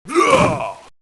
Heroes3_-_Vampire_-_DefendSound.ogg